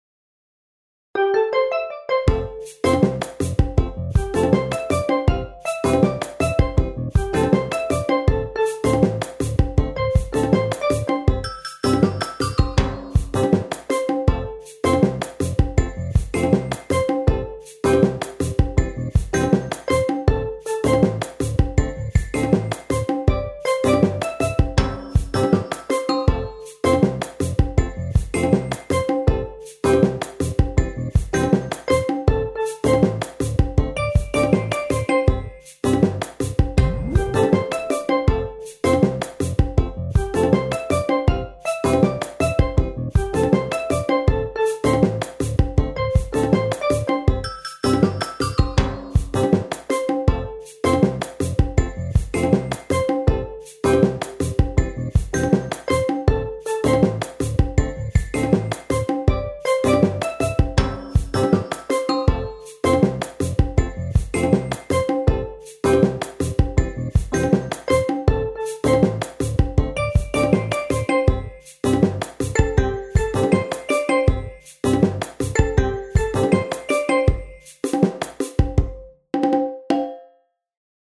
ショートスローテンポ明るい